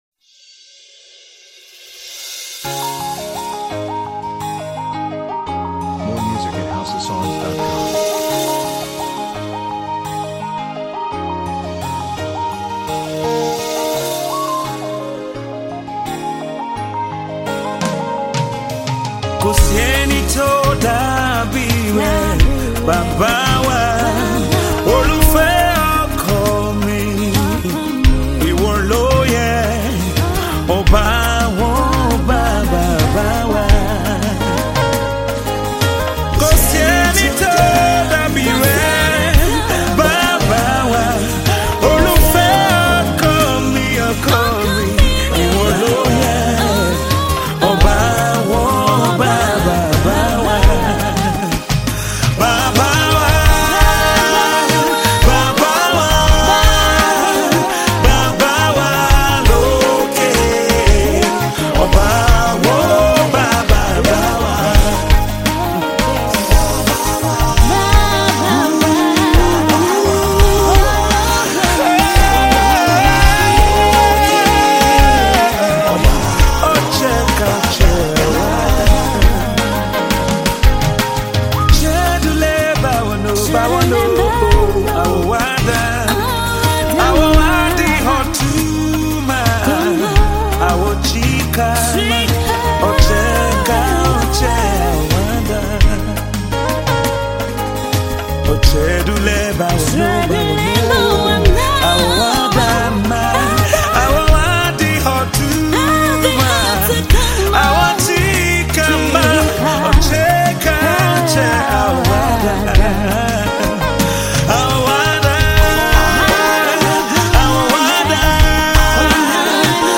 Tiv songs